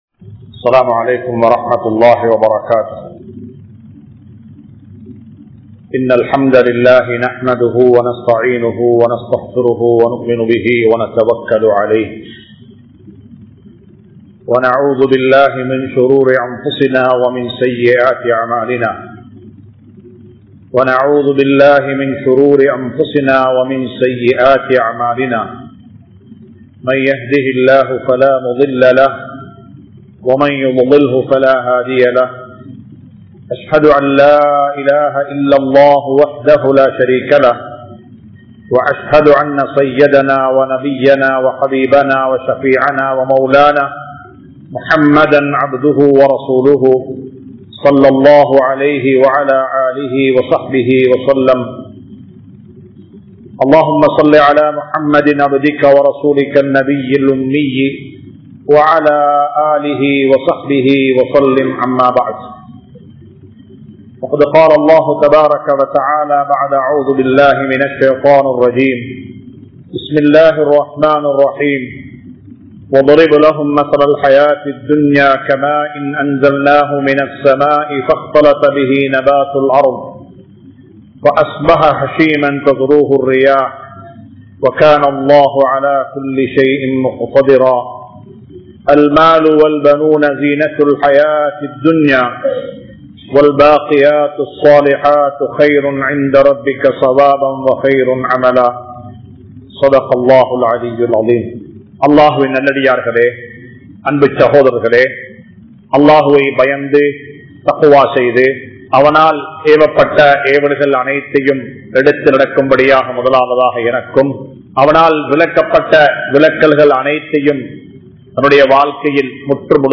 Inpangalai Alikkum Maranam | Audio Bayans | All Ceylon Muslim Youth Community | Addalaichenai